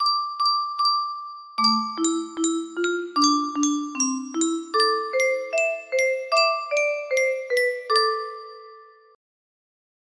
Italy music box melody